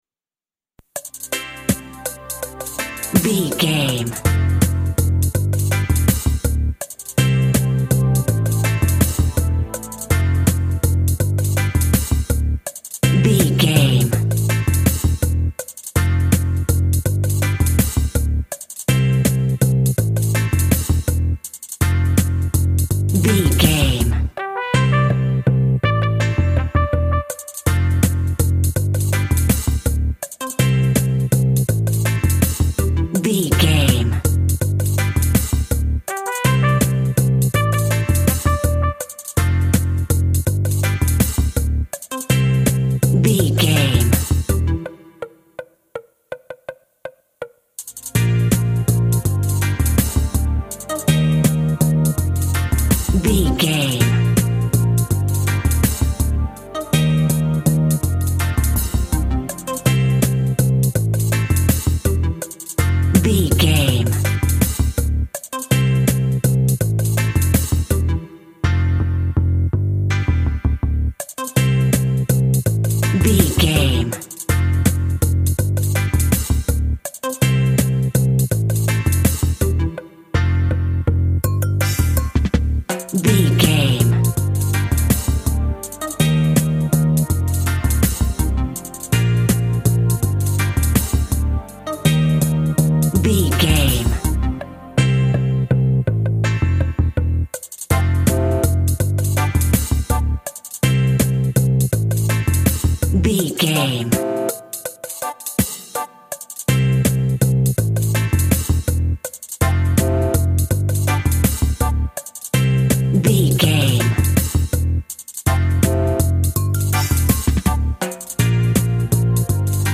Also with small elements of Dub and Rasta music.
Aeolian/Minor
tropical
drums
bass
guitar
piano
brass